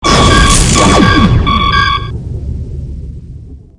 ShieldsFail.wav